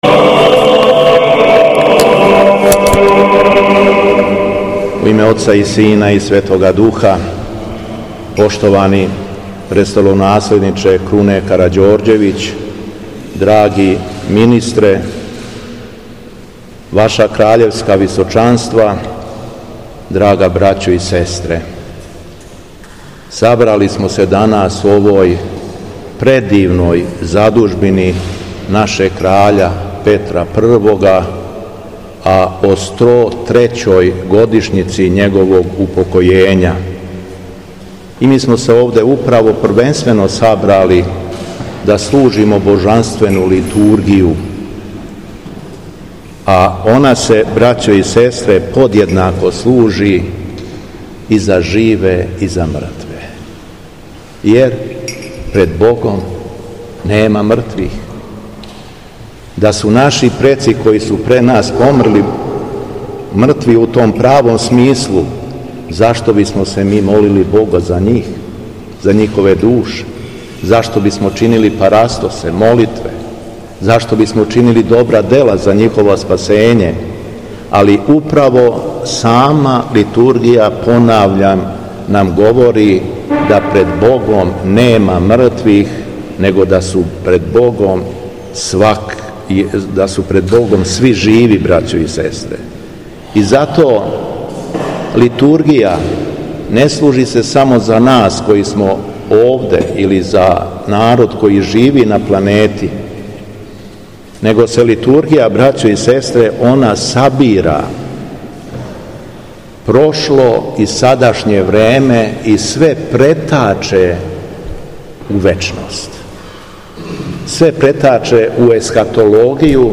Беседа Његовог Високопреосвештенства Митрополита шумадијског г. Јована